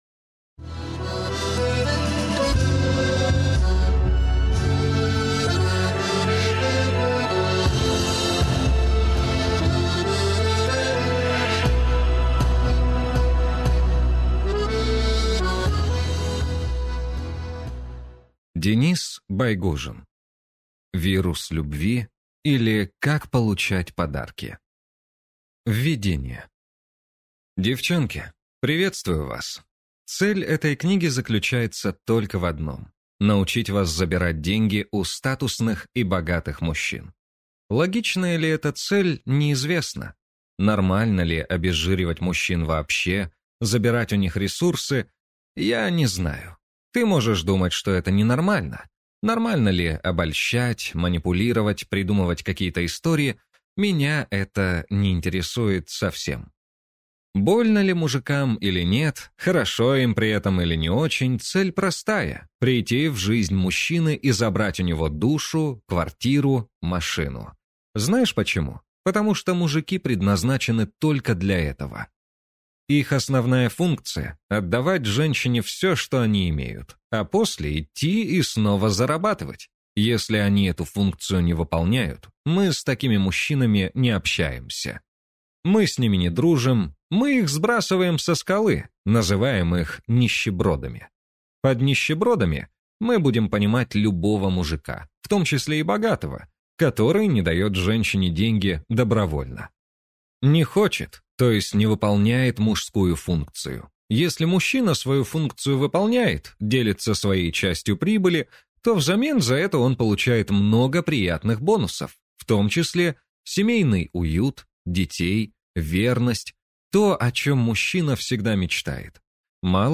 Аудиокнига Вирус любви, или Как получать подарки | Библиотека аудиокниг